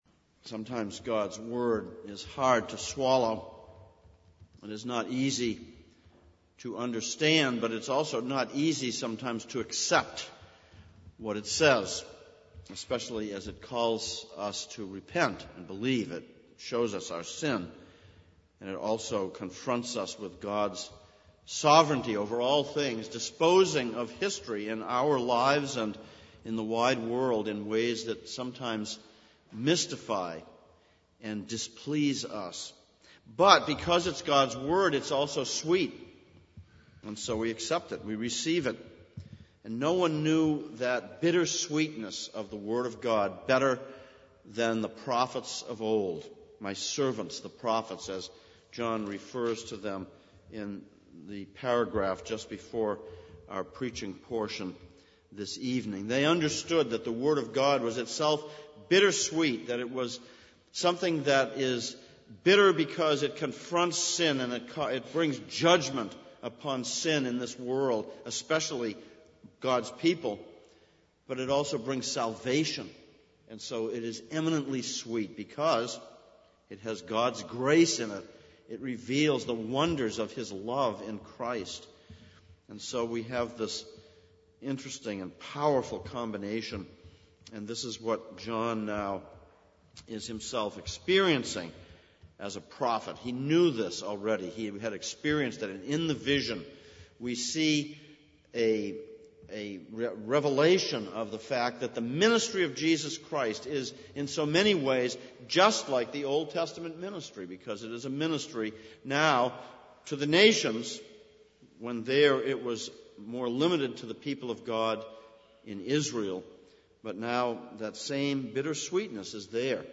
Sermon - "A Bittersweet Book" - Revelation 10:8-11; Ezekiel 2:1-3:3
Service Type: Sunday Evening